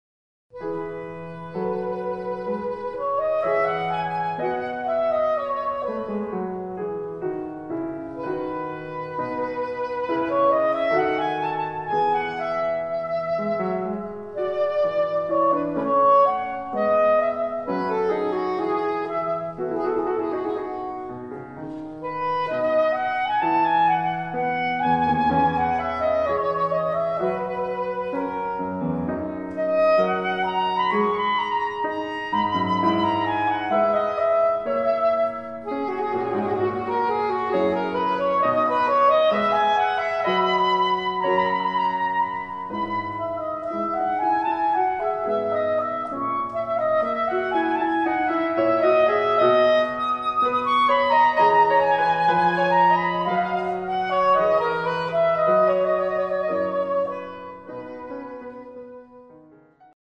saxophone
piano
Adagio